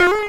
cartoon_boing_retro_jump_03.wav